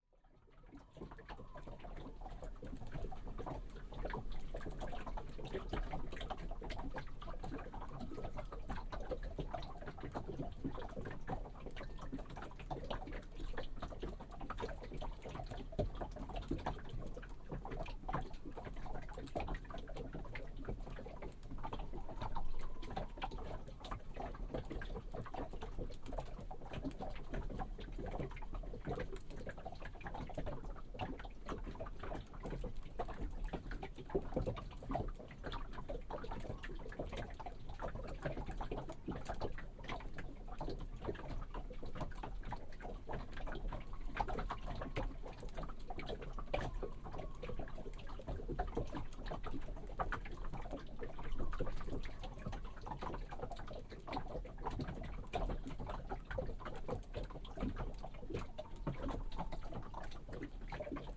Wellengluckern am geklinkerten Holzrumpf
ambeo-folkeboot-gluckern.mp3.mp3